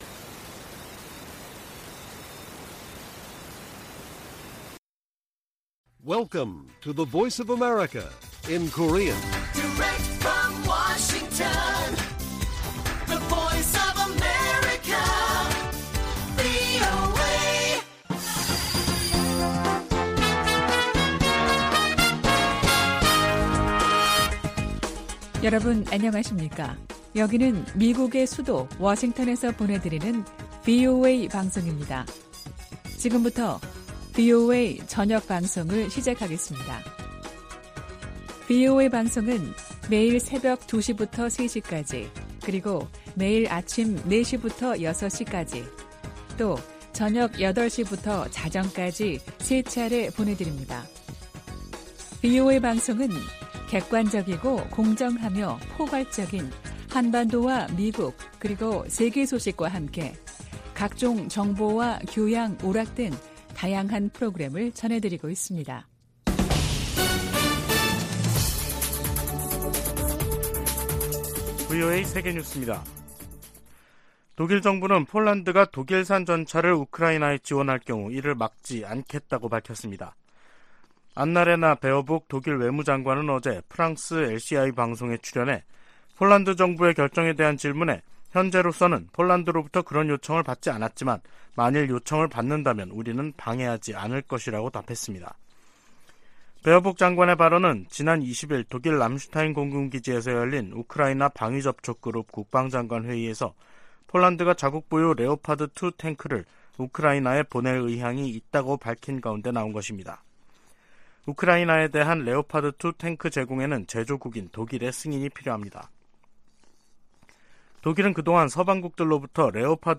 VOA 한국어 간판 뉴스 프로그램 '뉴스 투데이', 2023년 1월 23일 1부 방송입니다. 백악관이 북한과 러시아 용병그룹 간 무기 거래를 중단할 것을 촉구하고, 유엔 안보리 차원의 조치도 모색할 것이라고 밝혔습니다. 미 태평양공군은 한국 공군과 정기적으로 훈련을 하고 있으며, 인도태평양의 모든 동맹, 파트너와 훈련할 새로운 기회를 찾고 있다는 점도 강조했습니다.